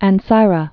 (ăn-sīrə)